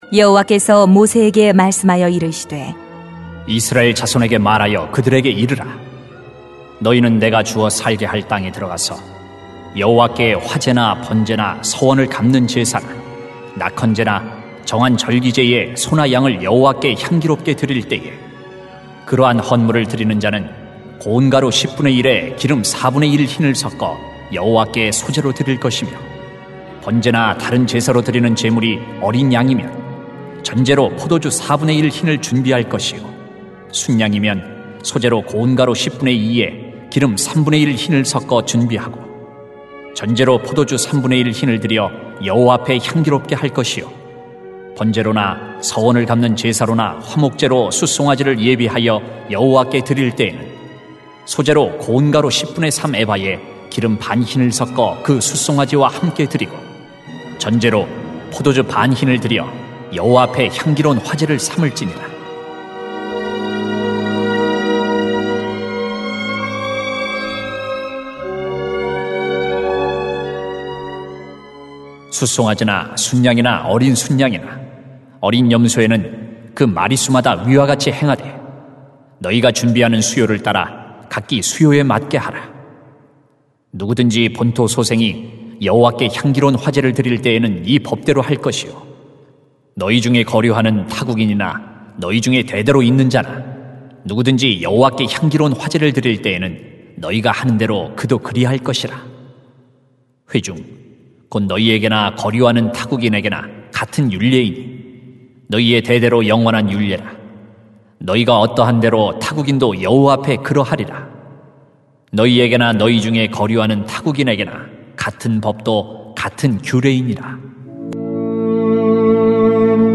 [민 15:1-21] 예배가 회복이 되면 소망이 있습니다 > 새벽기도회 | 전주제자교회